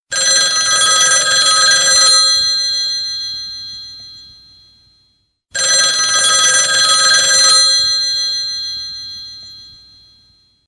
Android, Klassisk, Klassisk Telefon